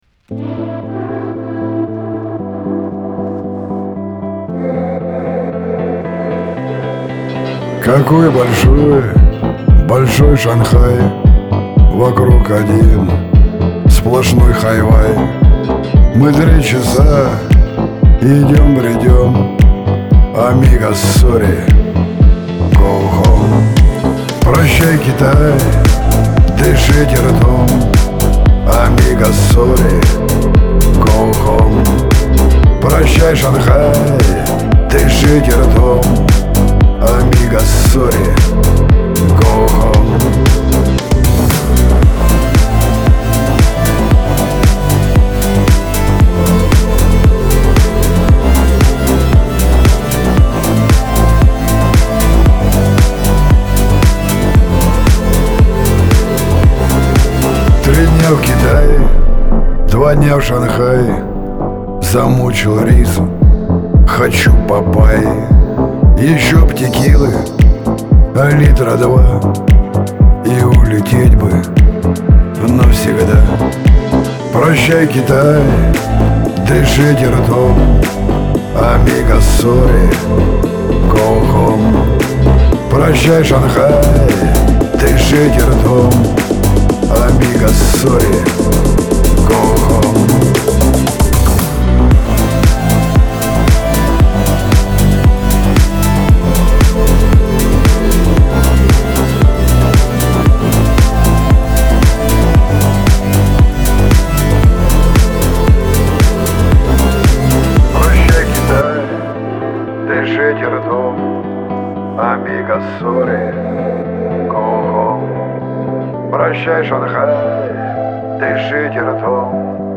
Лирика , Шансон
грусть